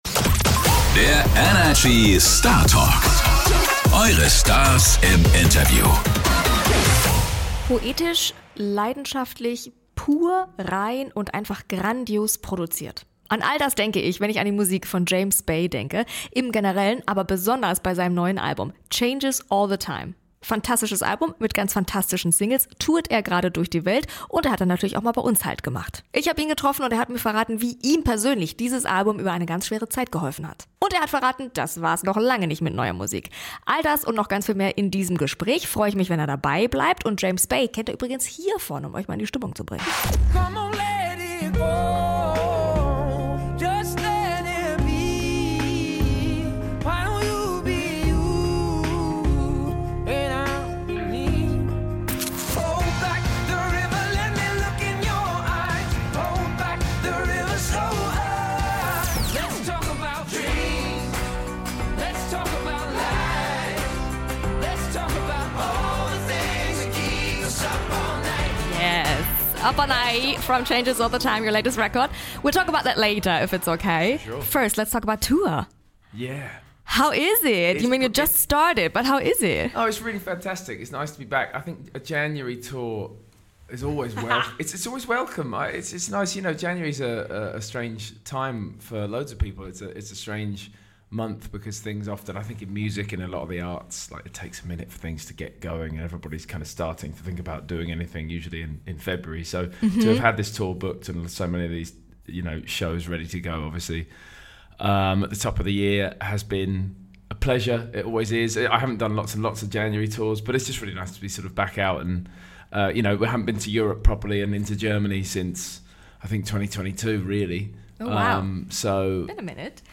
Beschreibung vor 1 Jahr Wir starten ins neue Jahr mit einem ganz besonderen Künstler: James Bay war bei uns zu Gast und hat über sein fantastisches neues Album "Changes All The Time" gesprochen. Alles über seine neuen Singles und wie ihm das Album über eine schwere Zeit hinweggeholfen hat, erfahrt ihr hier im ENERGY Startalk!